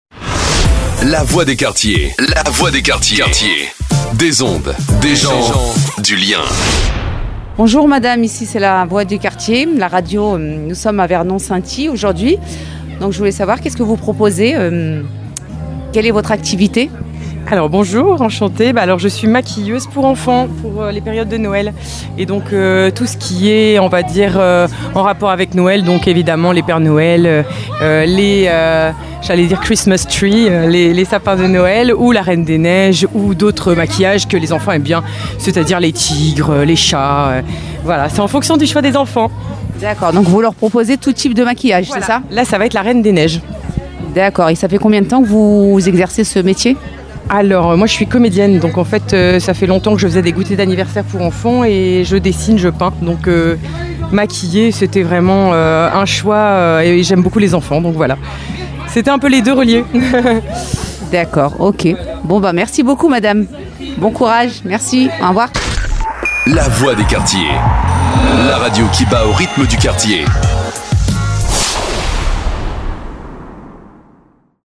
VERNON SCINTILLE DANS LES QUARTIERS INTERVIEW STAND DE MAQUILLAGE